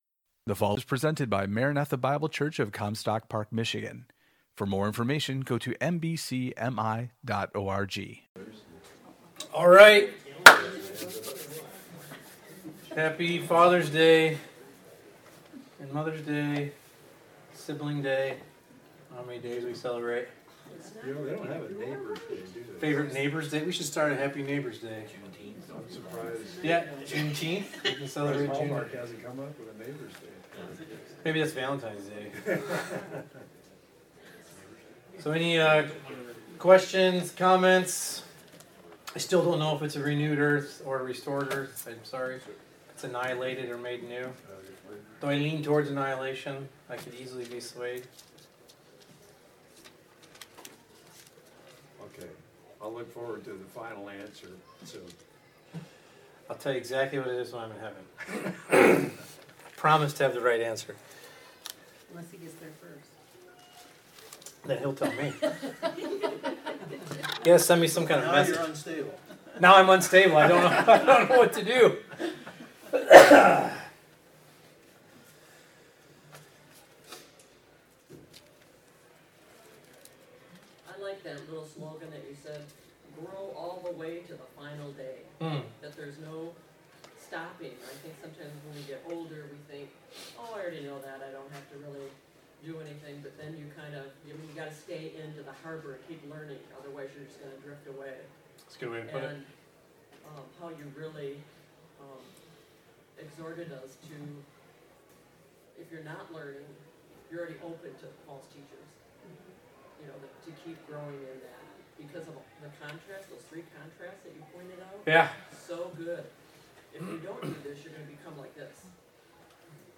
Equipping Hour – Sermon Discussion